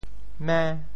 冥 部首拼音 部首 冖 总笔划 10 部外笔划 8 普通话 míng 潮州发音 潮州 mêng5 文 mên5 白 潮阳 mêng5 文 mên5 白 澄海 mêng5 文 mên5 白 揭阳 mêng5 文 mên5 白 饶平 mêng5 文 mên5 白 汕头 mêng5 文 mên5 白 中文解释 潮州 mêng5 文 对应普通话: míng ①昏暗：晦～。